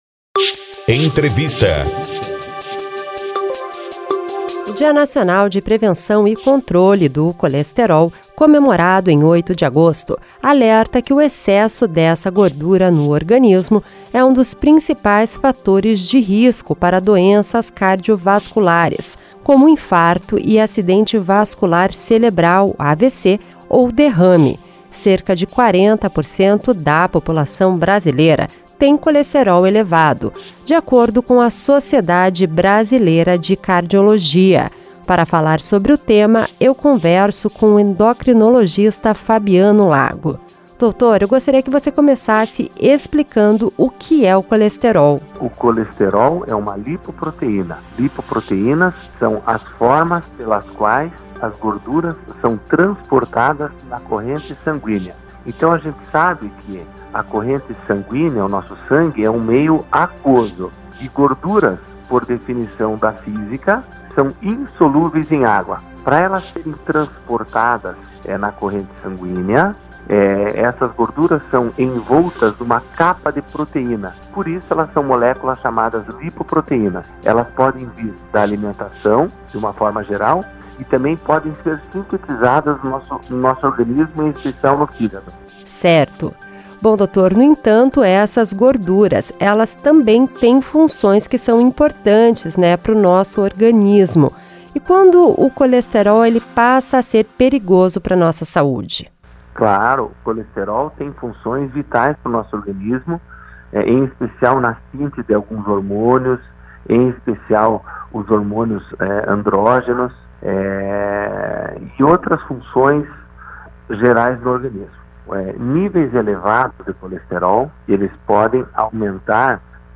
conversa com o endocrinologista